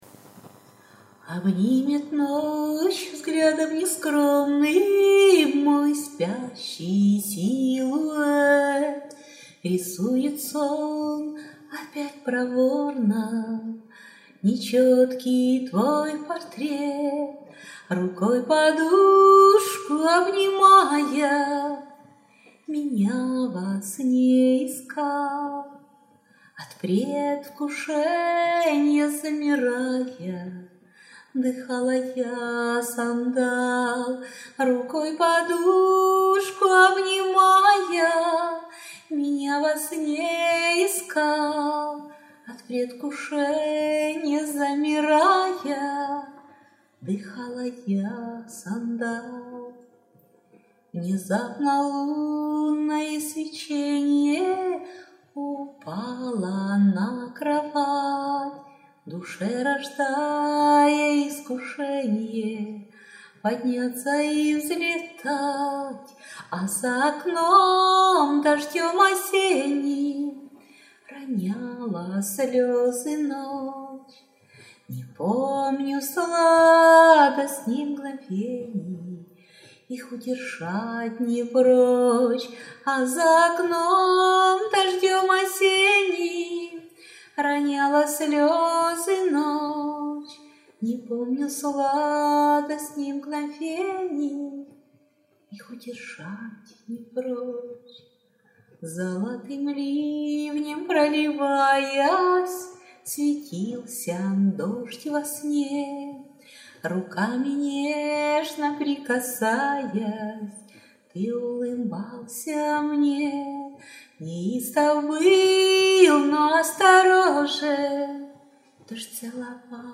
Рубрика: Поезія, Iнтимна лірика